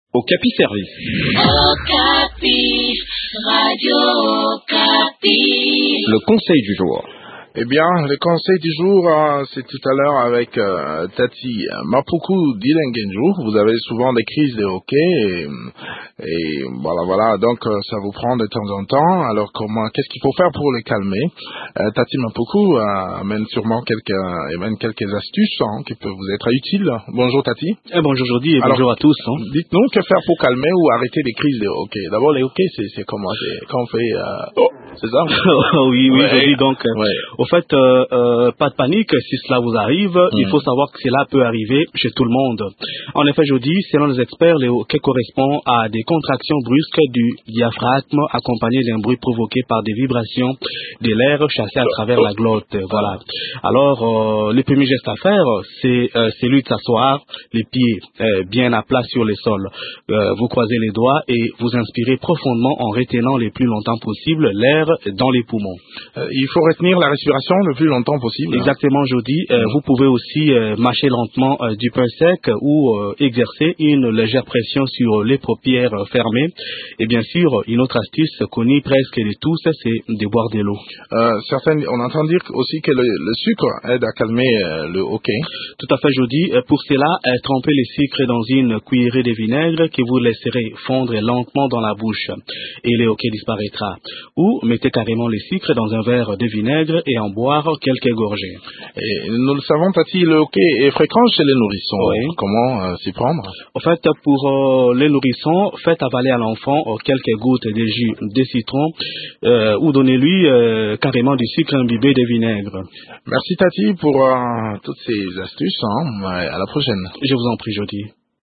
Découvrez des astuces qui peuvent vous être utiles dans cette chronique